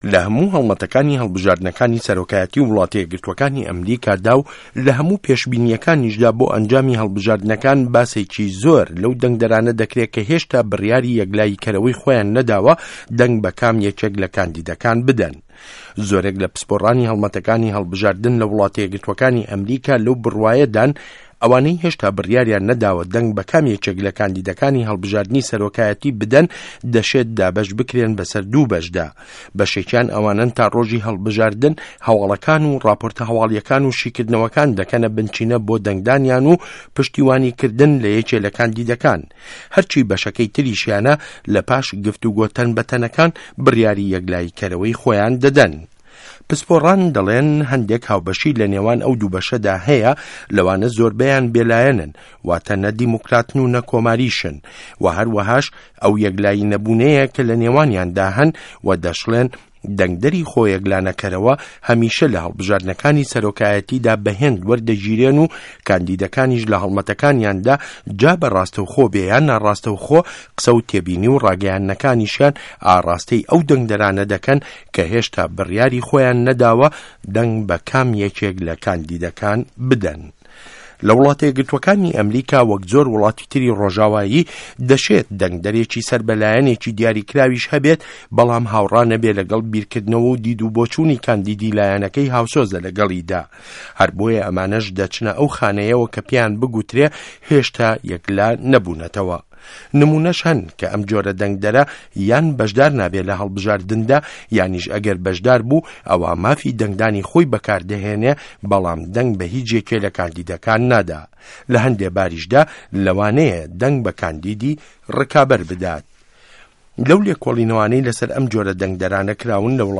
ڕاپۆرت له‌سه‌ر ده‌نگده‌رانی خۆیه‌کلانه‌که‌ره‌وه له‌ ئه‌مریکا